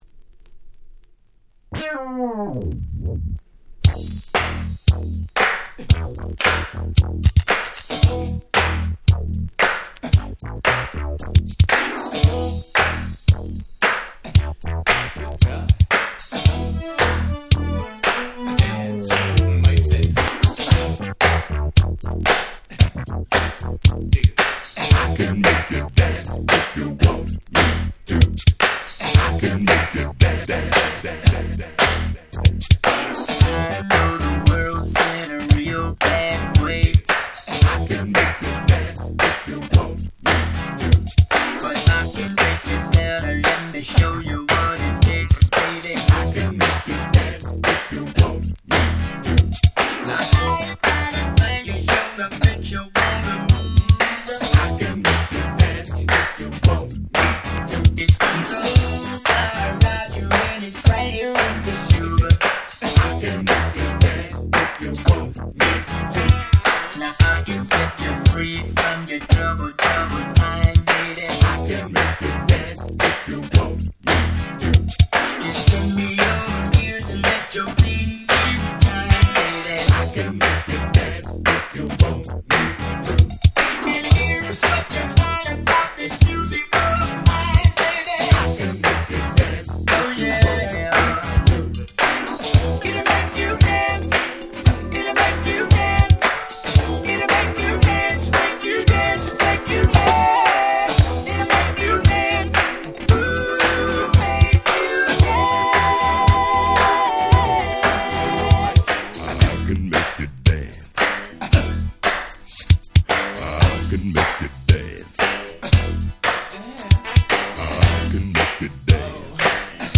83' Smash Hit Disco / Funk !!
ファンク ディスコ 80's